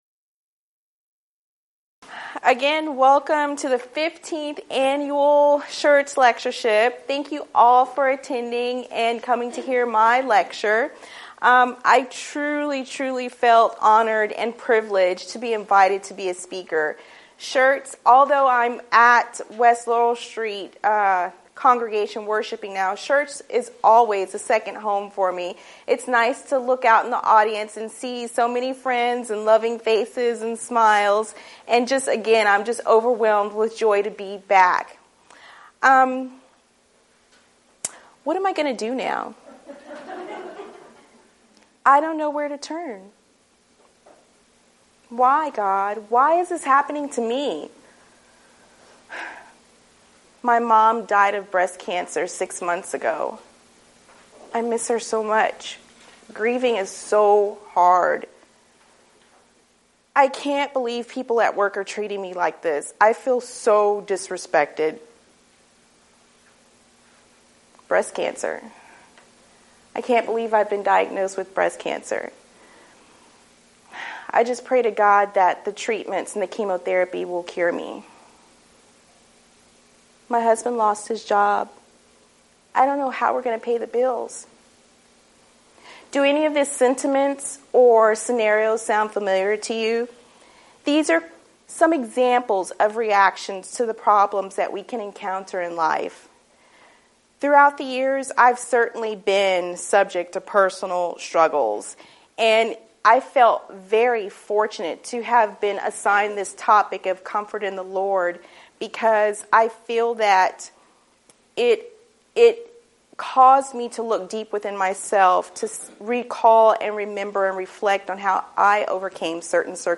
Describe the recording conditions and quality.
Event: 15th Annual Schertz Lectures